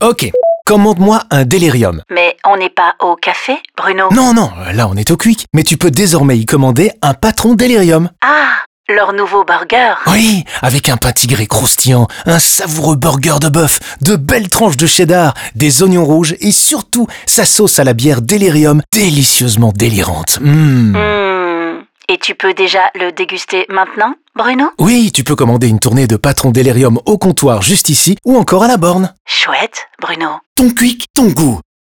En plus du concept de burger, Happiness vient alimenter la campagne avec un spot TV, un nouveau spot audio en restaurant, du (D)OOH en 2, 8, 10 et 20m2, du matériel POS et une campagne social media.
Quick_FR32s_PatronDelirium_InStore.wav